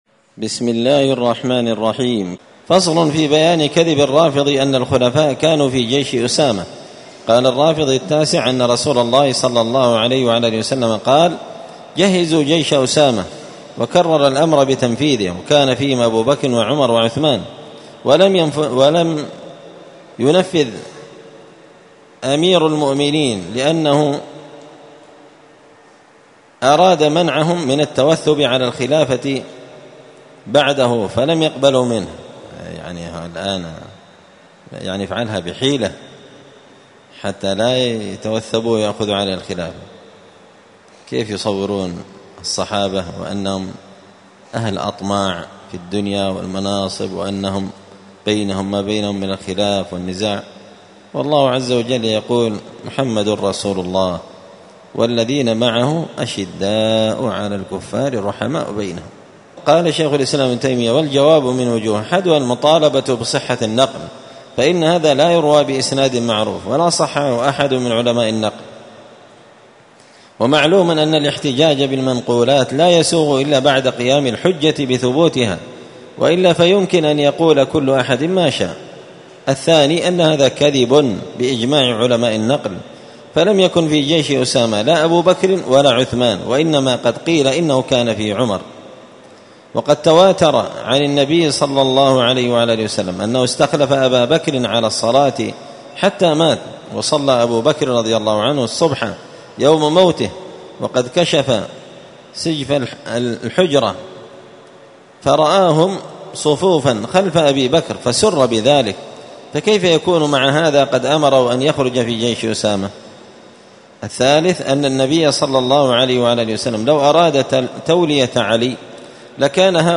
*الدرس الثاني والثلاثون بعد المائتين (232) فصل في بيان كذب الرافضي أن الخلفاء كانوا في جيش أسامة*